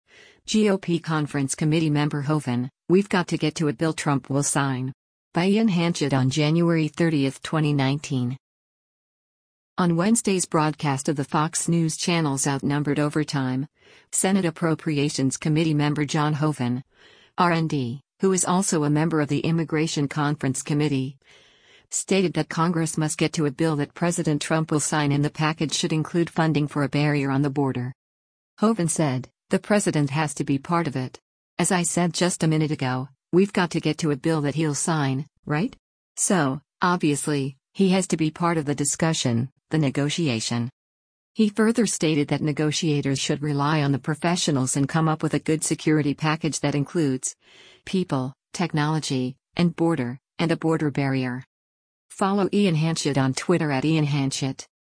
On Wednesday’s broadcast of the Fox News Channel’s “Outnumbered Overtime,” Senate Appropriations Committee member John Hoeven (R-ND), who is also a member of the immigration conference committee, stated that Congress must get to a bill that President Trump will sign and the package should include funding for a barrier on the border.